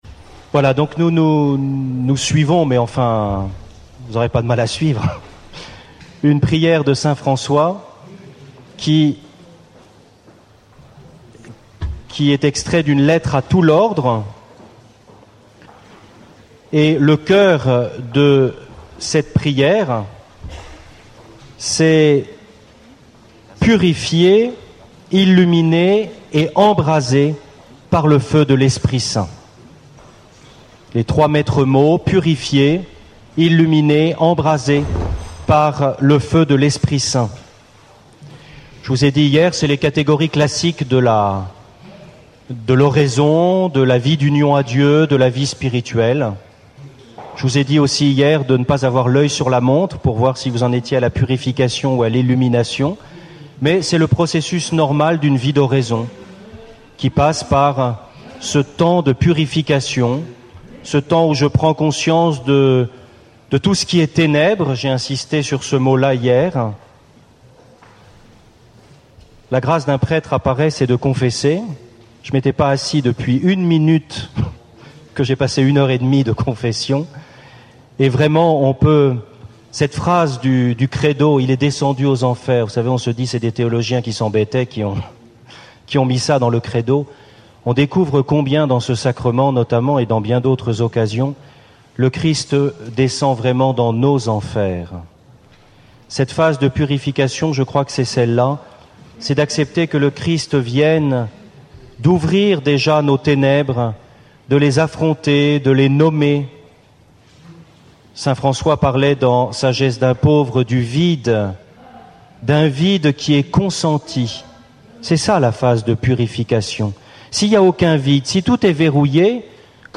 Enseignement
Session famille 3 (du 4 au 9 août 2012)